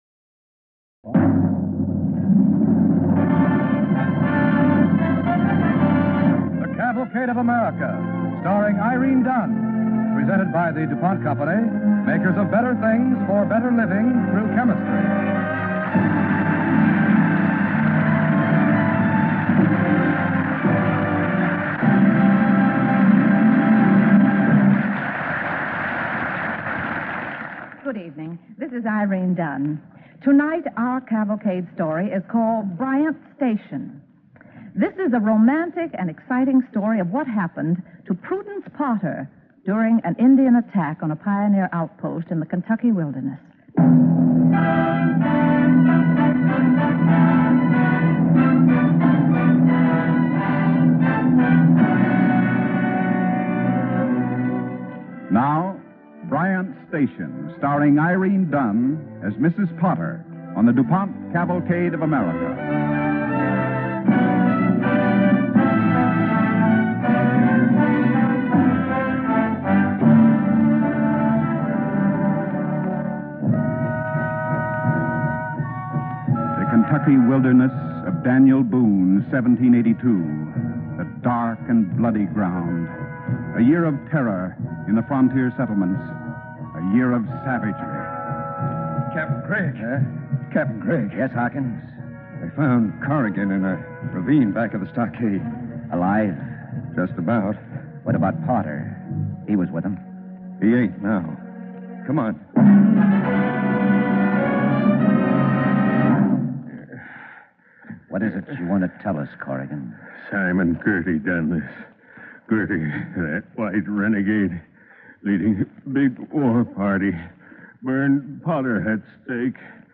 starring Irene Dunne